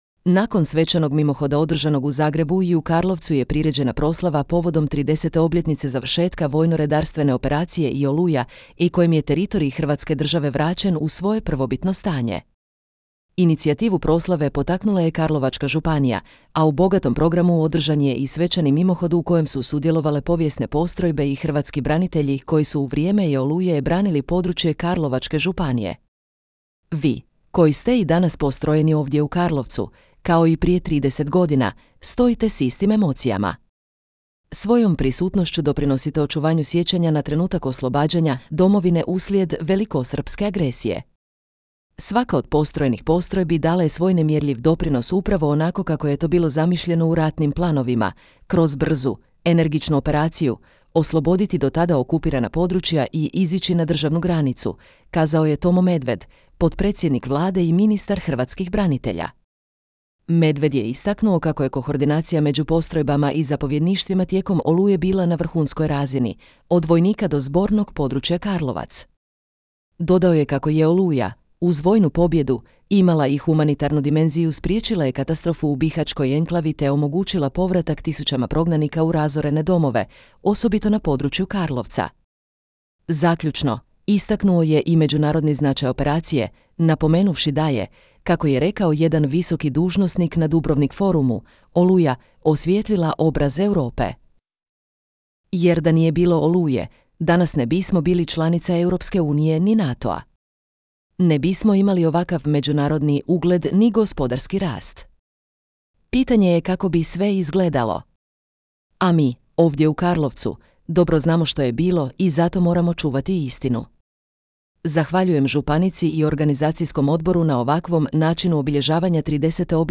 Svim okupljenima obratio se i saborski zastupnik i gradonačelnik Karlovca Damir Mandić.
Nakon održanih govora svim okupljenim braniteljima zapovjednici su podijelili spomenice i simbolične medalje te je započelo druženje.